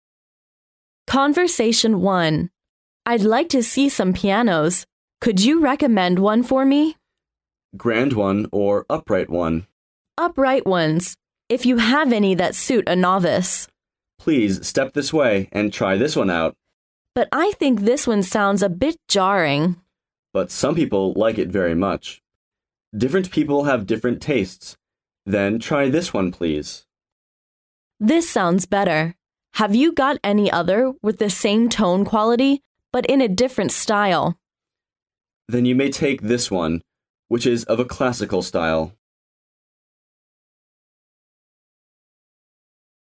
Conversation 1